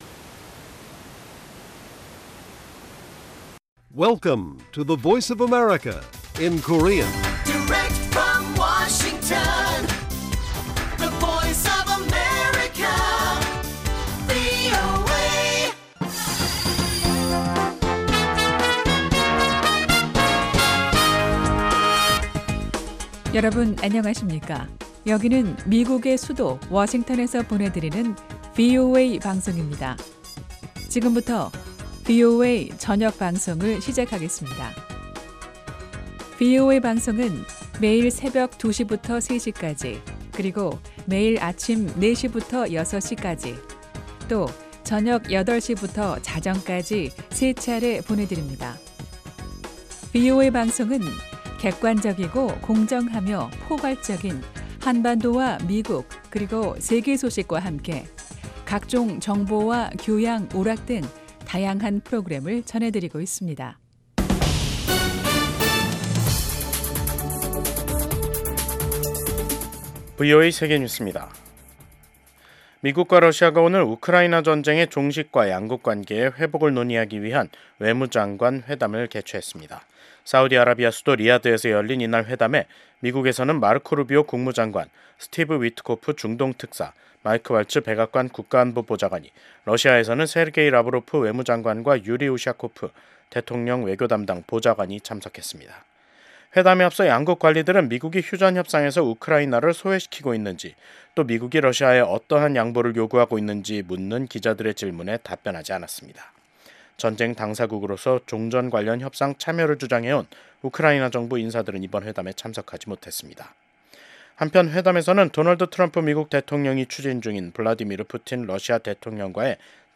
VOA 한국어 방송의 간판 뉴스 프로그램입니다. 한반도와 함께 미국을 비롯한 세계 곳곳의 소식을 빠르고 정확하게 전해드립니다. 다양한 인터뷰와 현지보도, 심층취재로 풍부한 정보를 담았습니다.